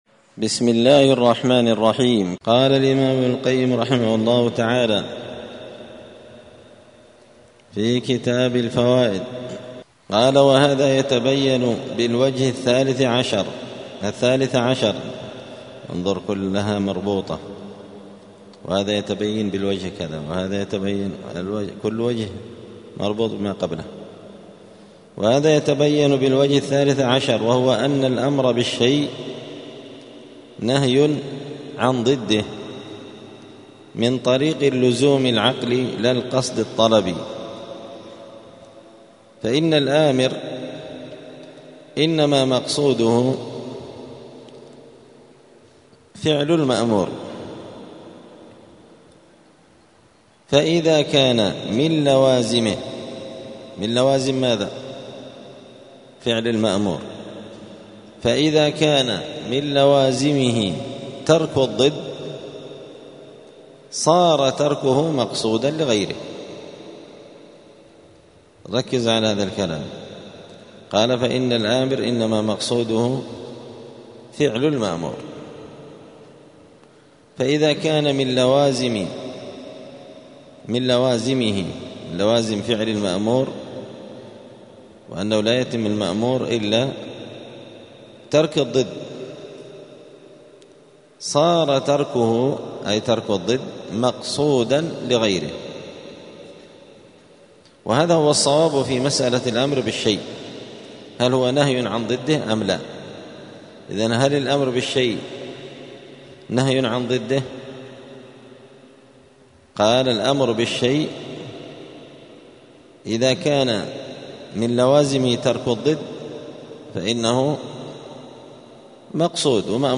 *الدرس الثامن والستون (68) {فائدة: أن الأمر بالشي نهي عن ضده}*